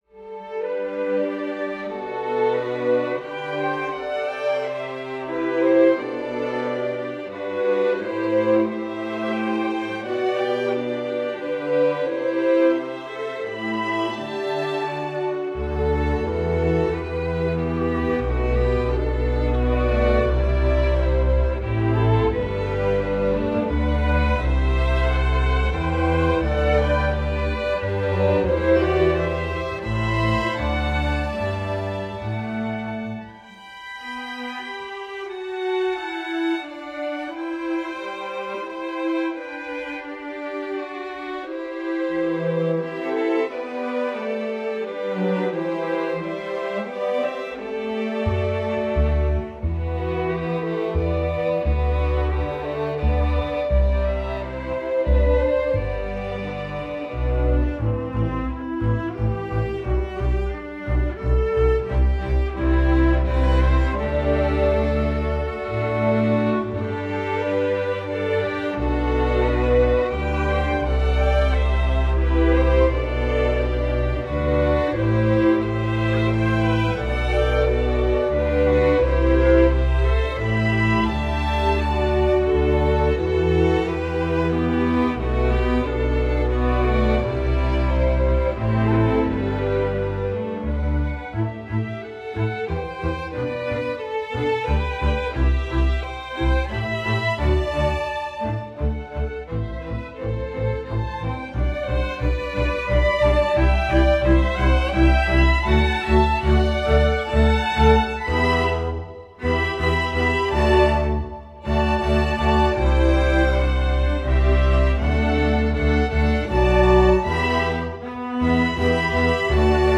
Category: String Orchestra
Instrumentation: str